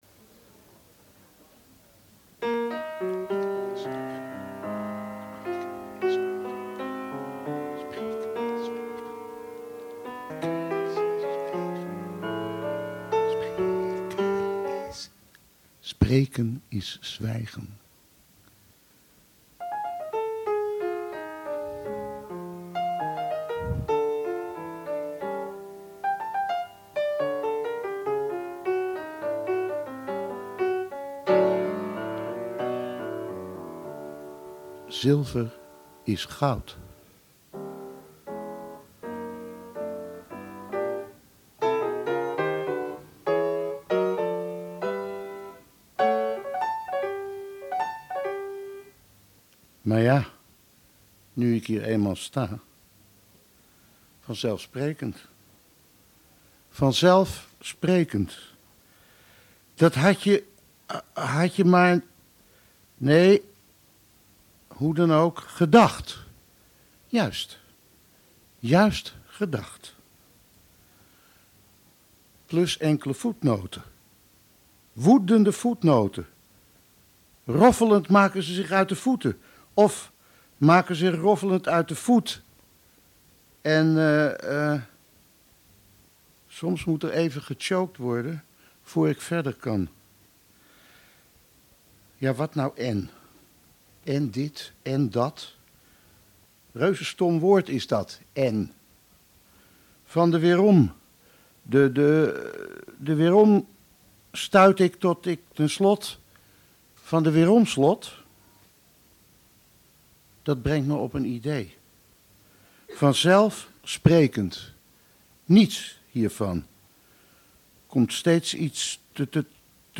Monoloog geschreven en voorgelezen door Bernlef, begeleid door Guus Janssen op de piano.